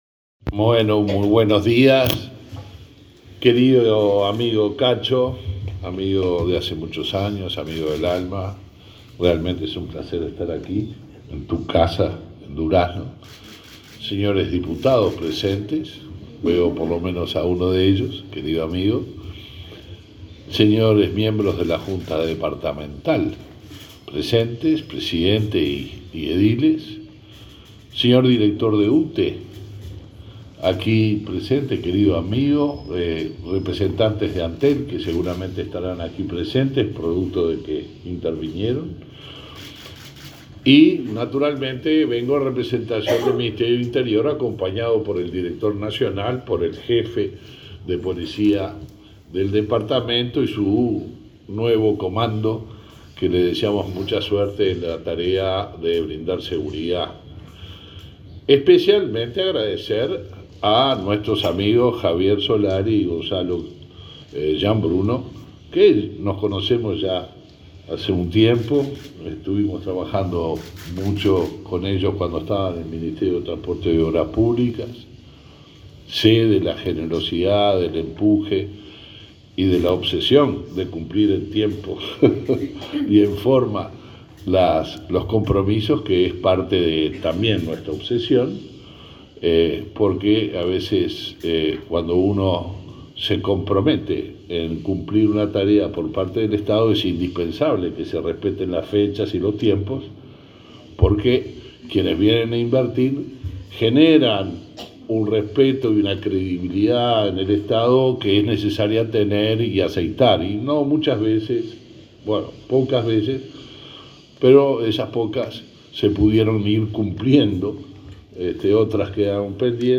Palabras del ministro del Interior, Luis Alberto Heber